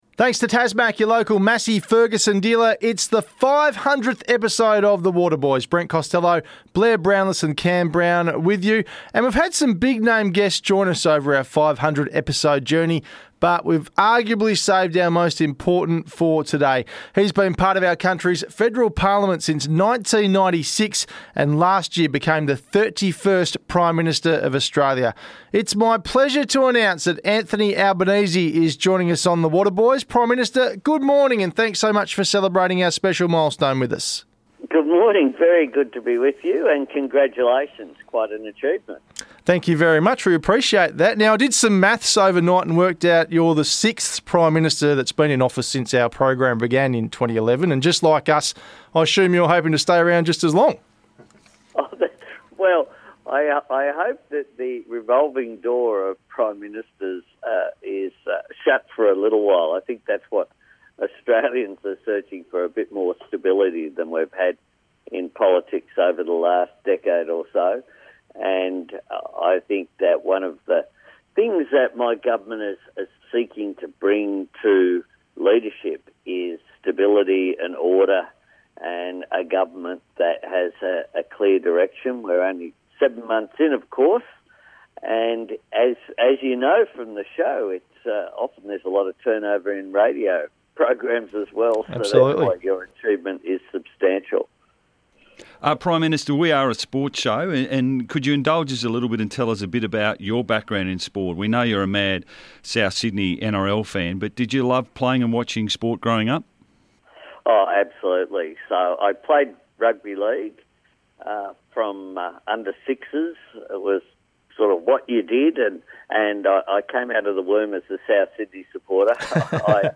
Anthony Albanese Interview - January 21st 2023